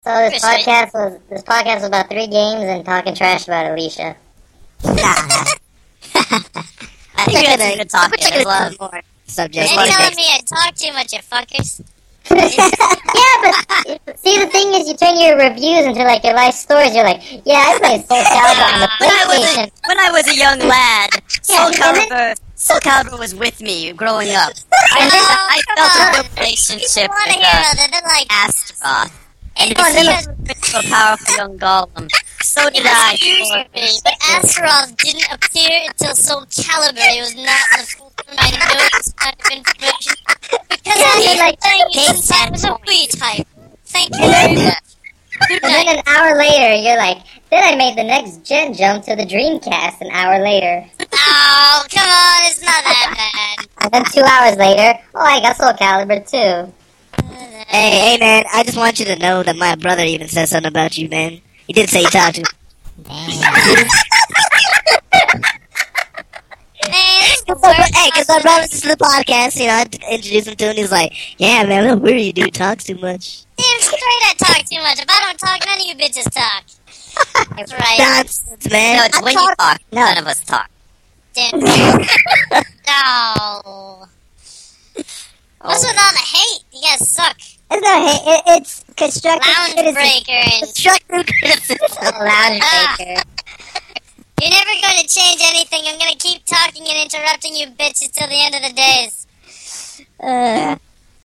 EDIT: I also added some "off-air" bits.